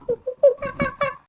Monkey Imitation 3
Category 🐾 Animals
animal animals ape apes chimp chimpanzee chimpanzees chimps sound effect free sound royalty free Animals